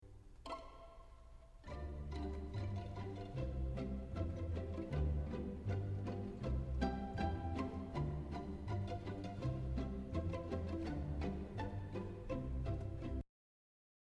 pianissimo-example.mp3